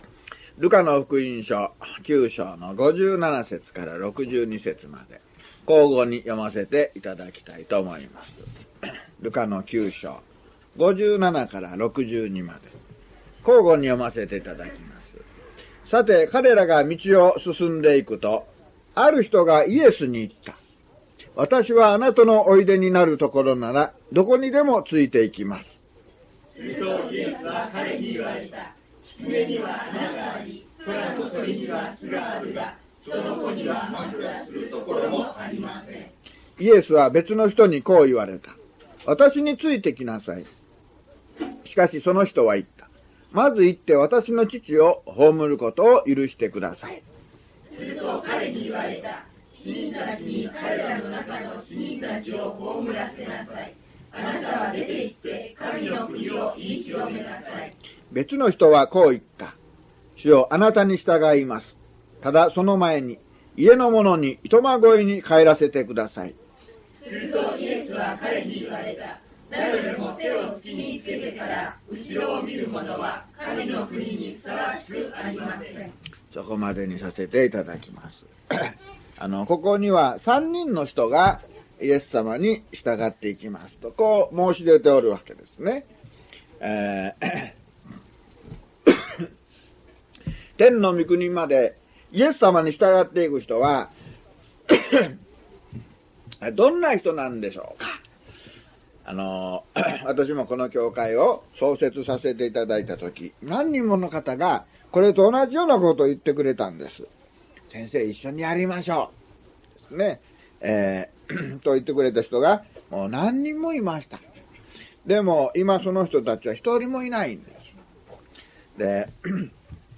luke070mono.mp3